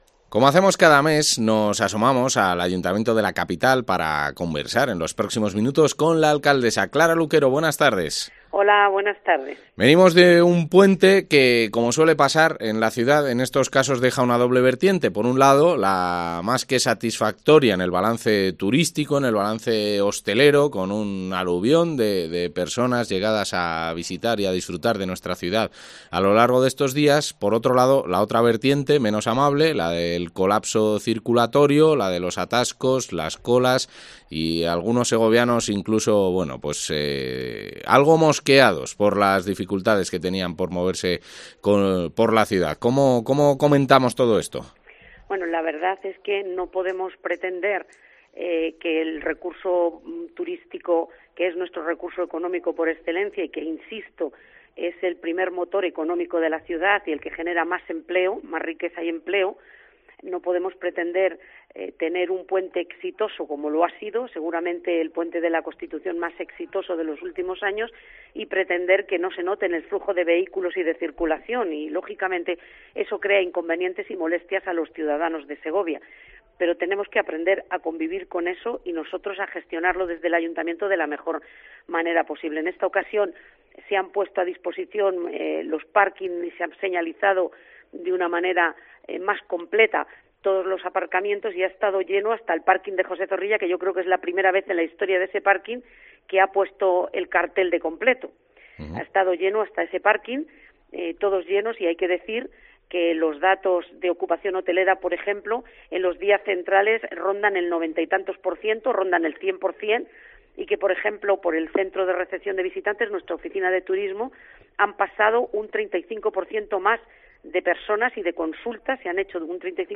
Entrevista a Clara Luquero